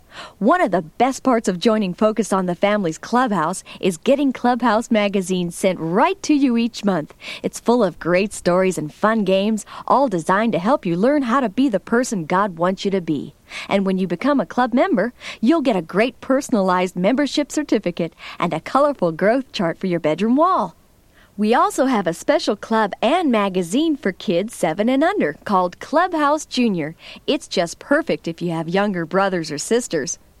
This promo for Clubhouse Magazine is from the end of first broadcast of #1: “Whit's Flop” on November 21, 1987.
Clubhouse_Promo_(1987).mp3